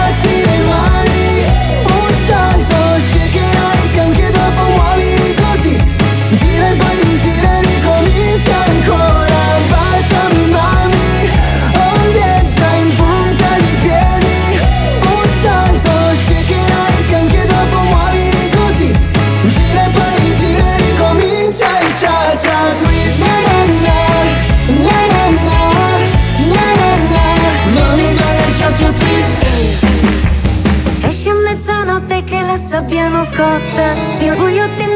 Pop